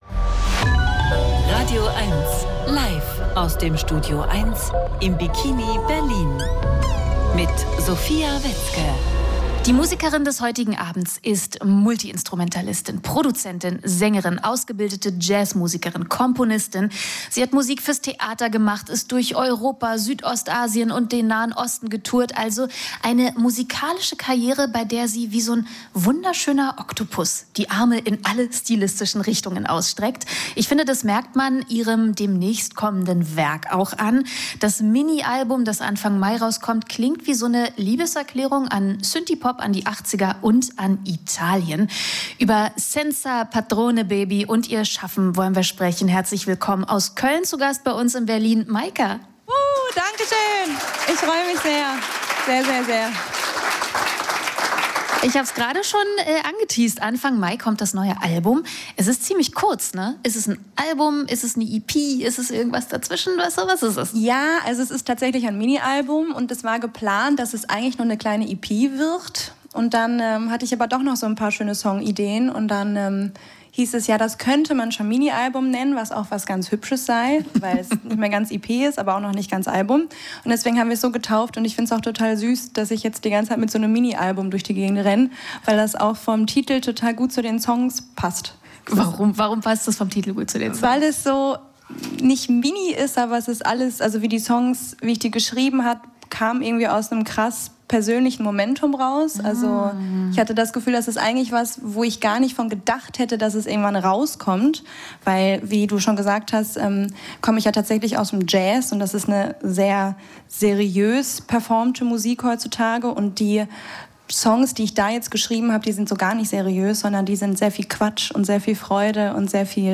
Heute besucht sie uns im studioeins, um im Interview über sich und ihre neue EP zu sprechen, und anschließend auch einen Eindruck davon zu geben, wie diese Songs live klingen.